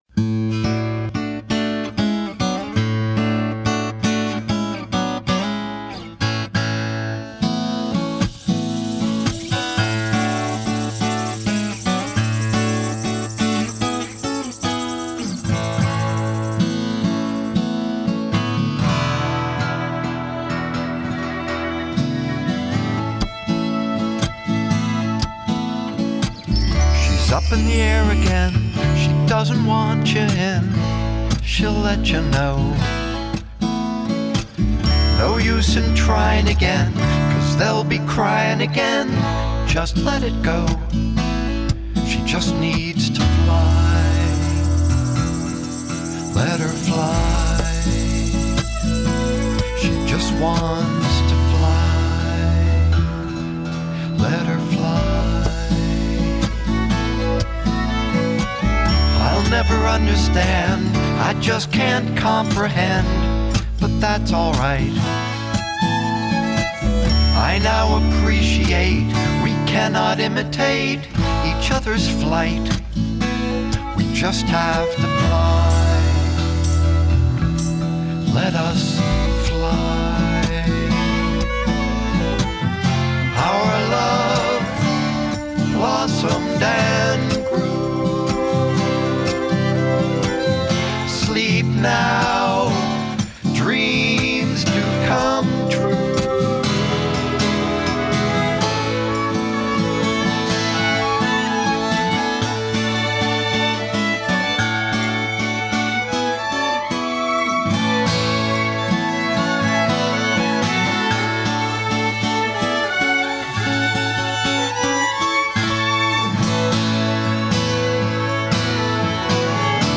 REMASTERED SONGS